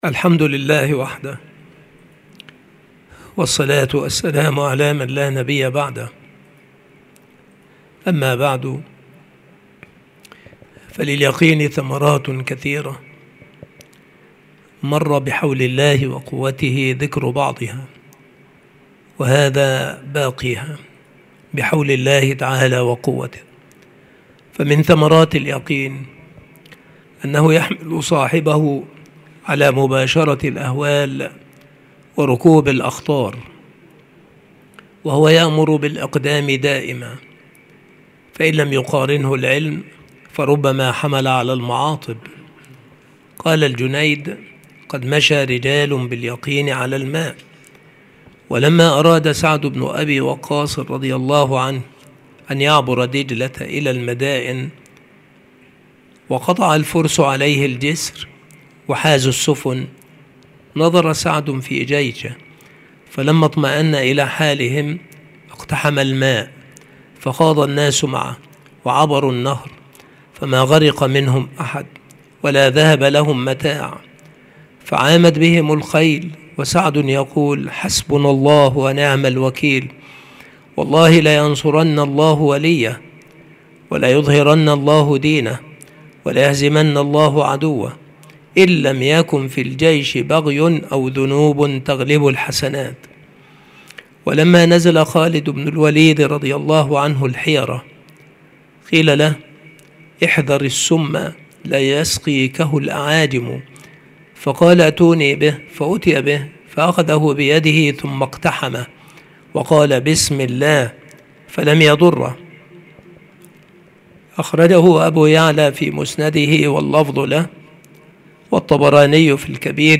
المحاضرة
مكان إلقاء هذه المحاضرة المكتبة - سبك الأحد - أشمون - محافظة المنوفية - مصر عناصر المحاضرة : تابع ثمرات اليقين.